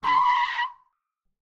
skid3.ogg